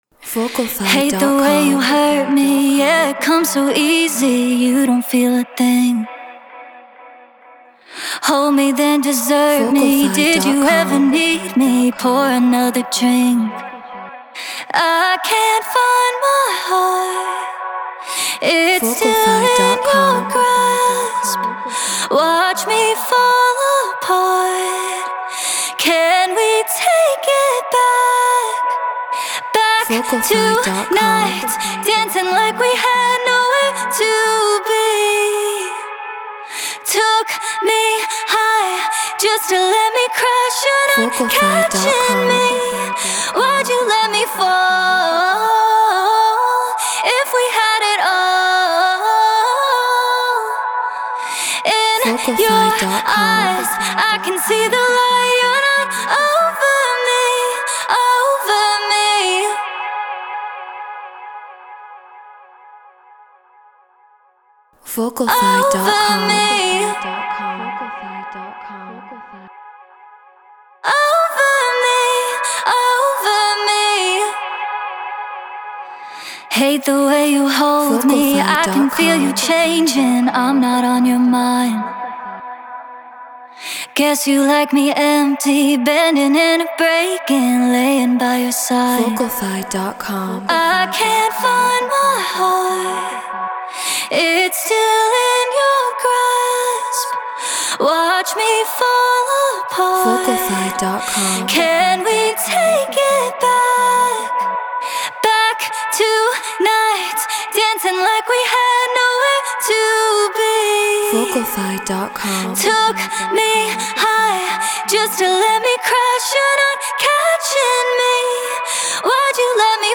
Progressive House 130 BPM Amaj
Treated Room